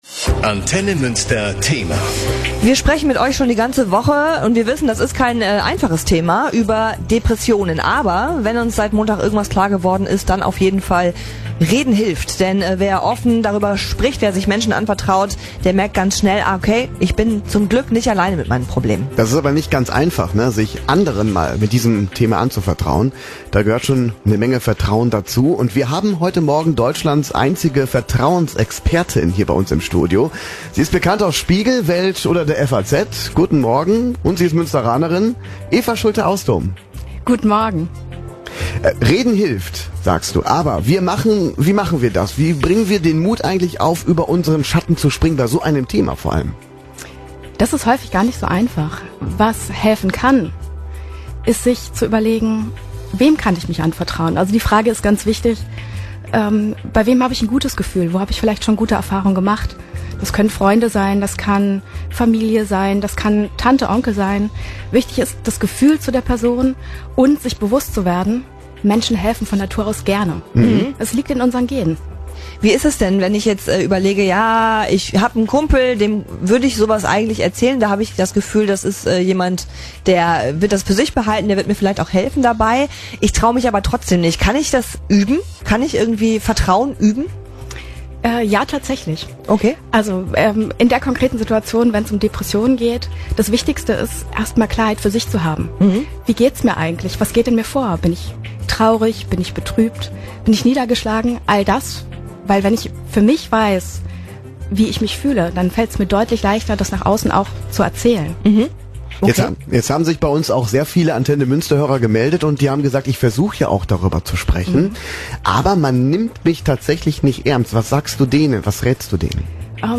Ein Gespräch über Offenheit und Vertrauen